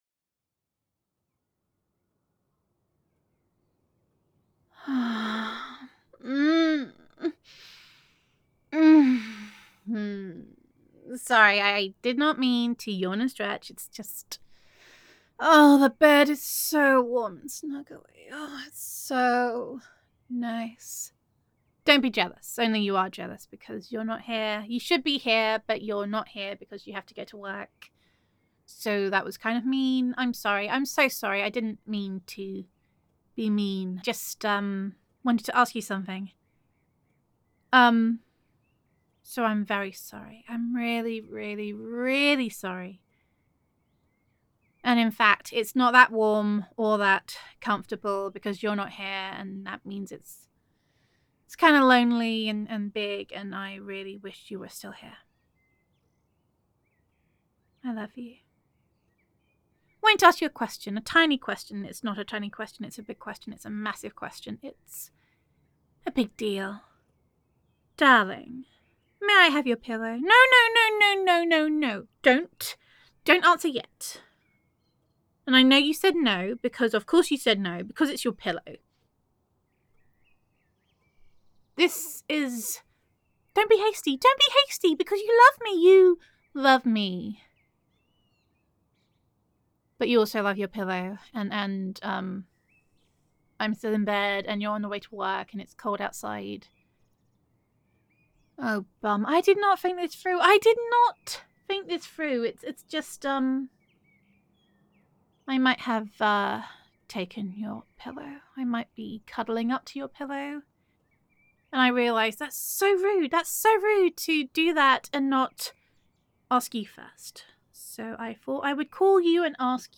[F4A] May I Have Your Pillow? [Girlfriend Roleplay][Girlfriend Voicemail][The Bed Is Lonely Without You][Early Morning][Your Pillow Is the Best][Reluctant to Get Up][Gender Neutral][Your Girl Wants to Ask You a Little Question]